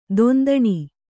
♪ dondaṇi